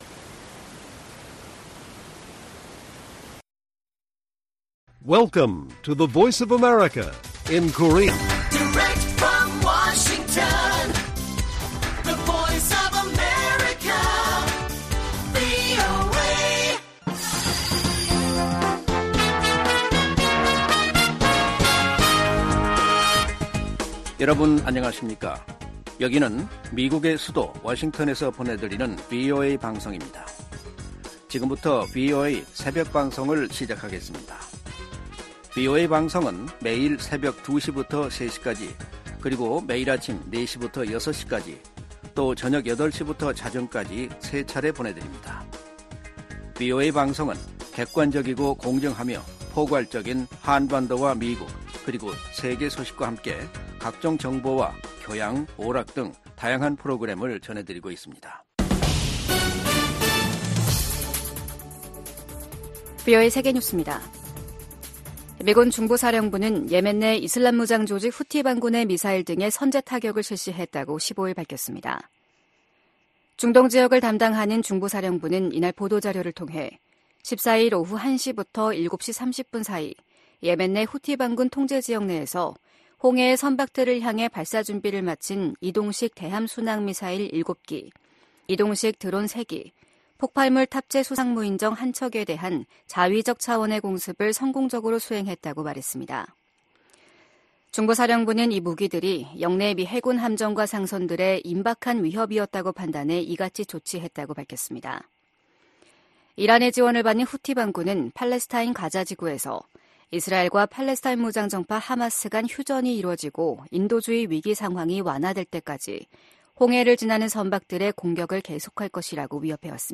VOA 한국어 '출발 뉴스 쇼', 2024년 2월 17일 방송입니다. 미국 고위 당국자들이 북한-러시아 관계에 우려를 나타내며 국제 협력의 중요성을 강조했습니다. 김여정 북한 노동당 부부장은 일본 총리가 평양을 방문하는 날이 올 수도 있을 것이라고 말했습니다.